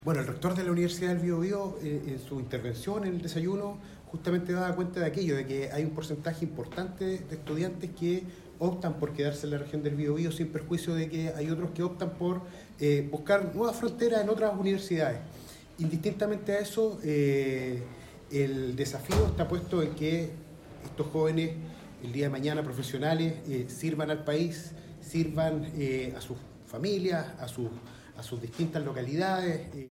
Mientras desayunaban en un mesón amplio en el Salón Biobío de la Delegación Presidencial Regional, las autoridades preguntaron a las y los estudiantes a qué carreras y universidades quieren postular, lo que permitió constatar que la mayoría tiene intenciones de quedarse en la Región del Biobío.